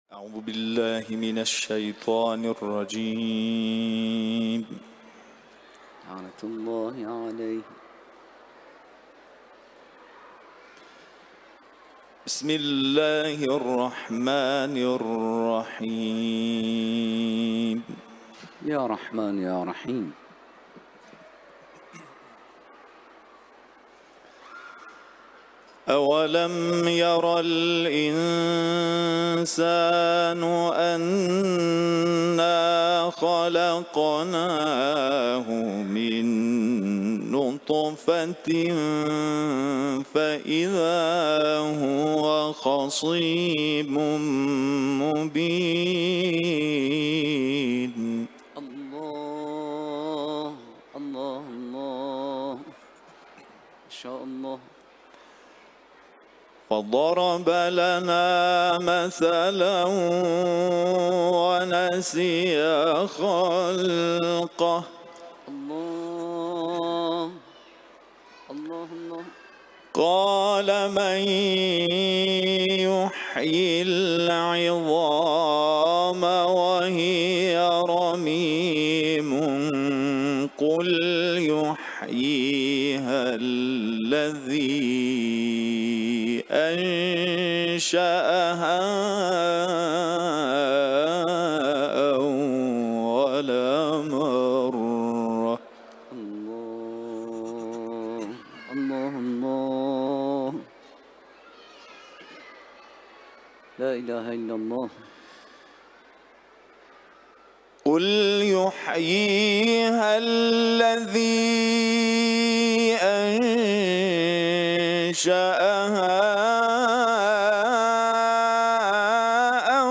Etiketler: İranlı kâri ، Kuran tilaveti ، Yasin sûresi